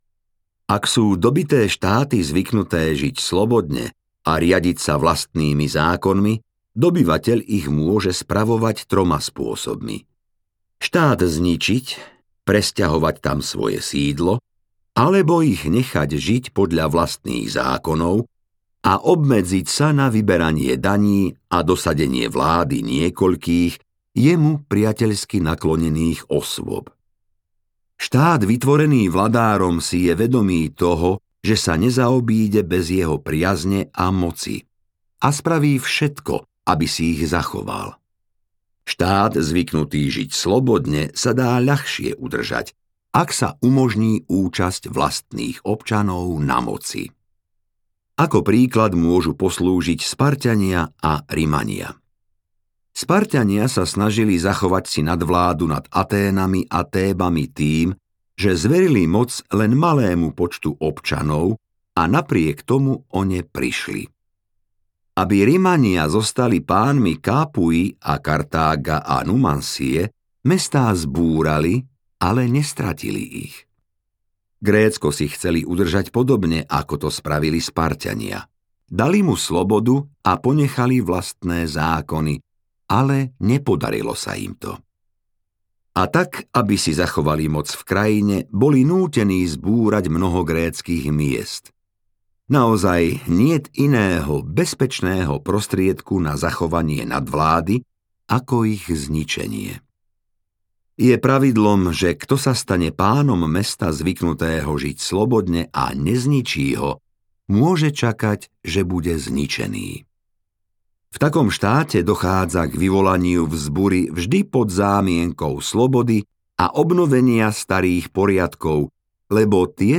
Vladár audiokniha
Ukázka z knihy